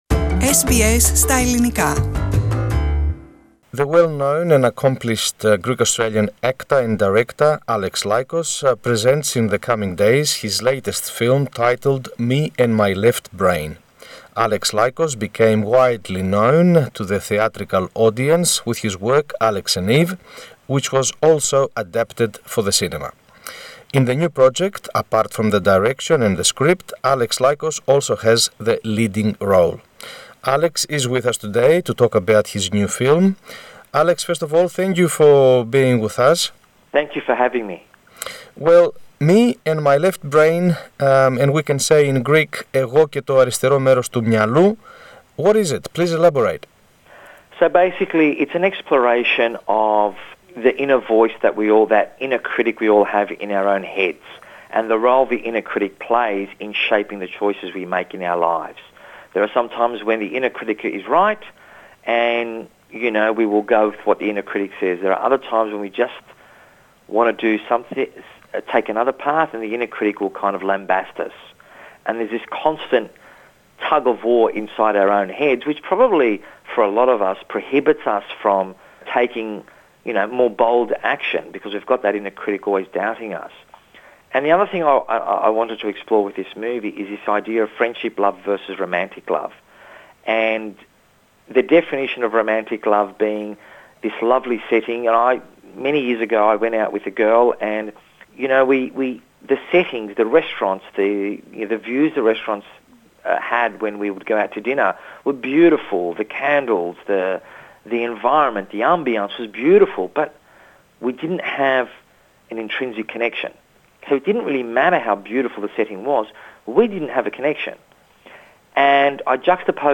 Furthermore, he elaborates on how independent producers like him self can have their films shown to the big theatres. The interview is in English.